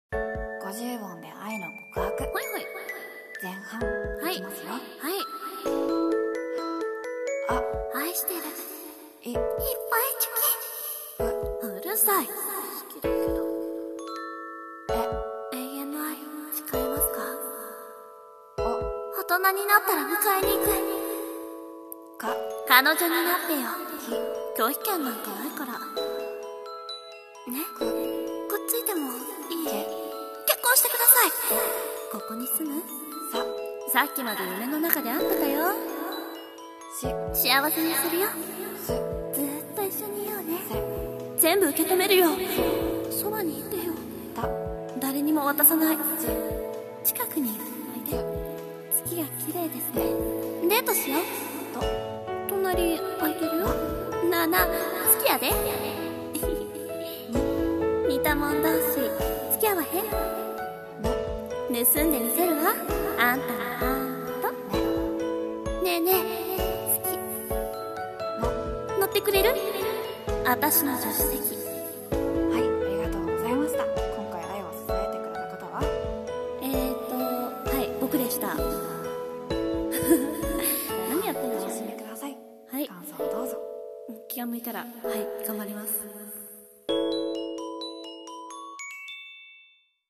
[声面接]